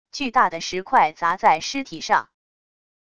巨大的石块砸在尸体上wav音频